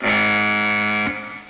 BUZZ.mp3